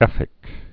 (ĕfĭk)